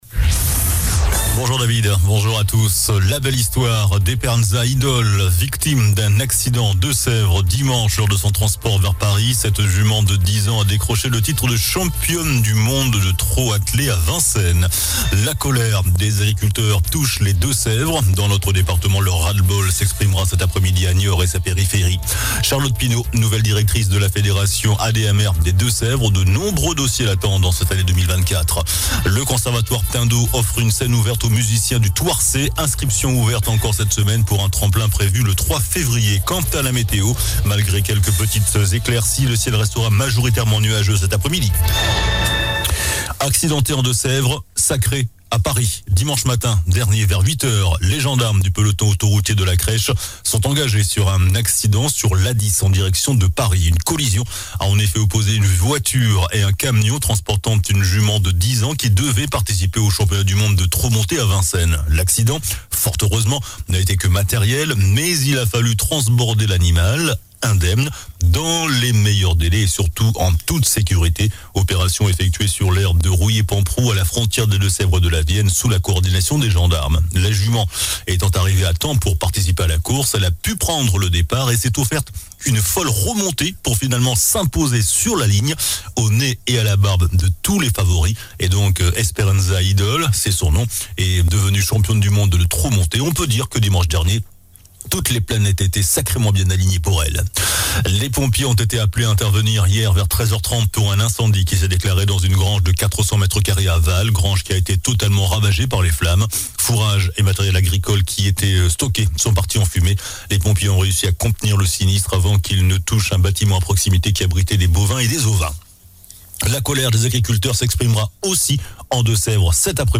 JOURNAL DU MARDI 23 JANVIER ( MIDI )